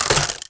player_takes_damage.ogg